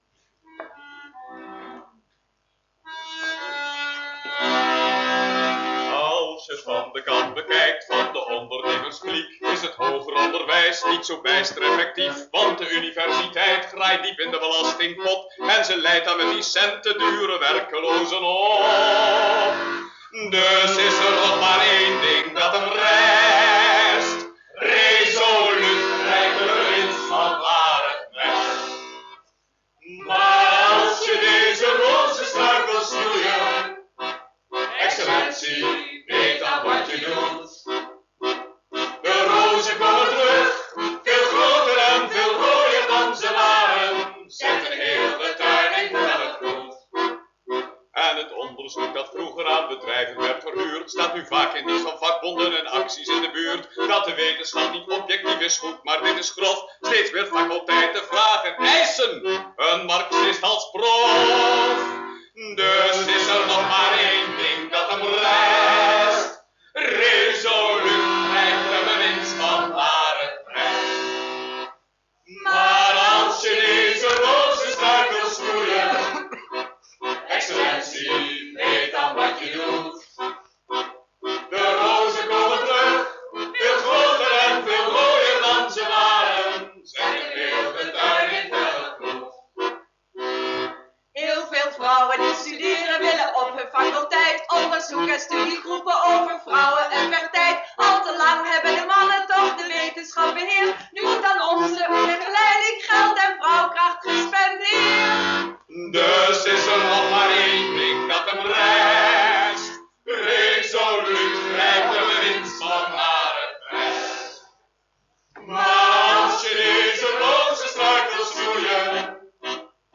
Opname tijdens een Try Out met een cassetterecorder, matige kwaliteit.